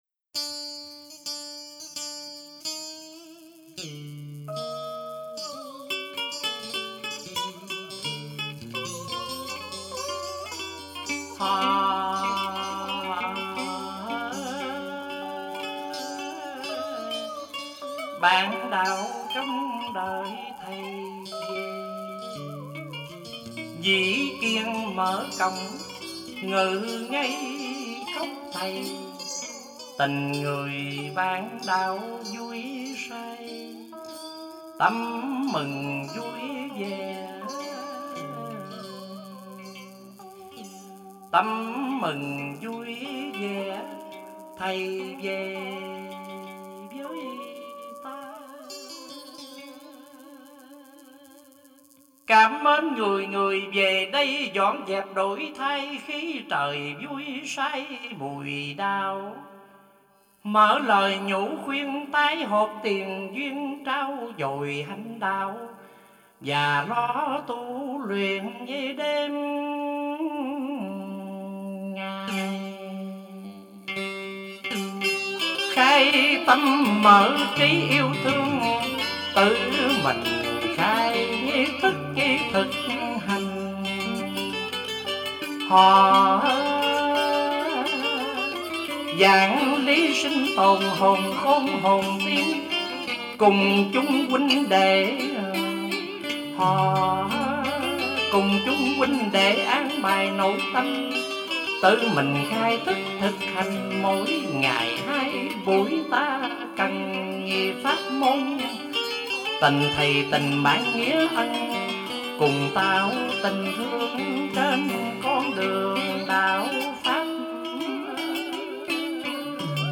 Dân Ca & Cải Lương